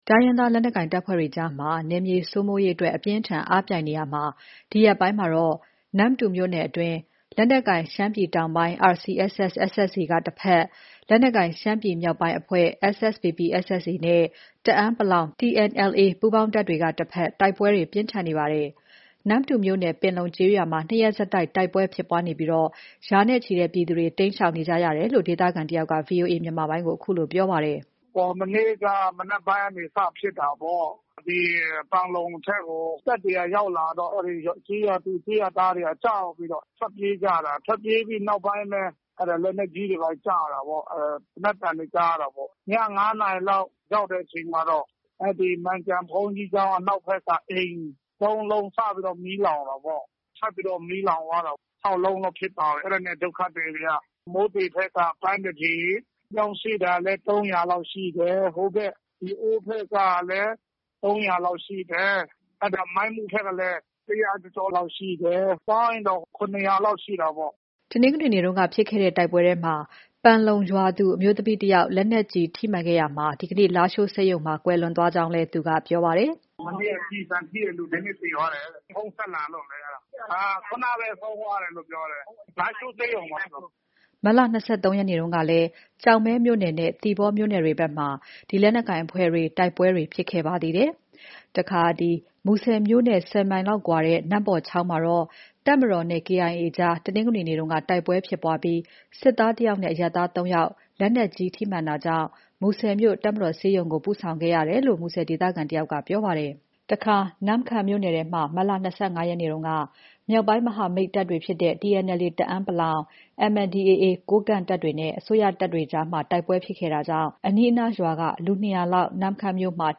နမ္မတူမြို့နယ် ပန်လုံကျေးရွာမှာ ၂ ရက်ဆက်တိုက် တိုက်ပွဲဖြစ်ပွားနေပြီး ရာနဲ့ချီတဲ့ ပြည်သူတွေ တိမ်းရှောင်နေရကြောင်းကို ဒေသခံတစ်ဦးက ဗွီအိုအေကို အခုလို ပြောပါတယ်။